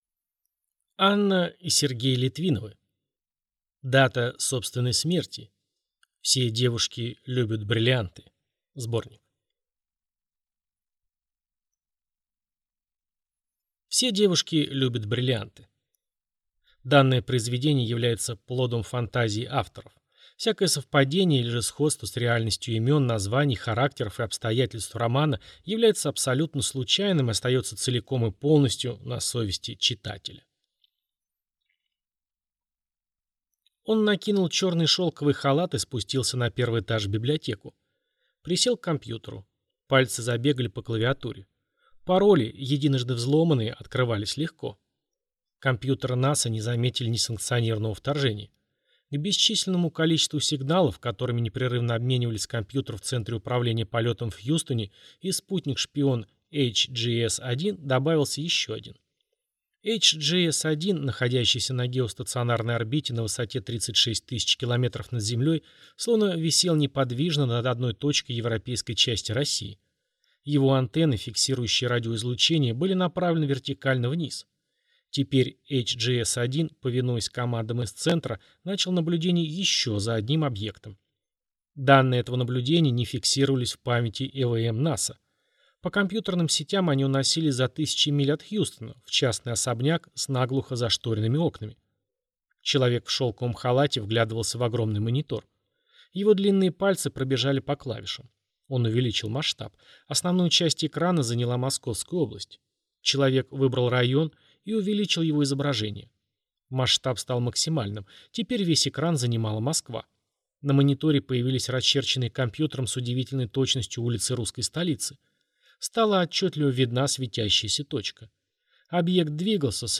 Аудиокнига Дата собственной смерти. Все девушки любят бриллианты (сборник) | Библиотека аудиокниг